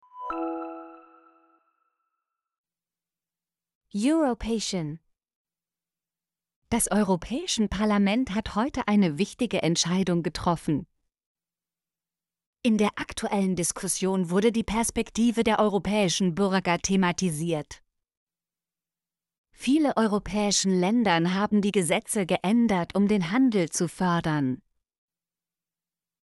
europäischen - Example Sentences & Pronunciation, German Frequency List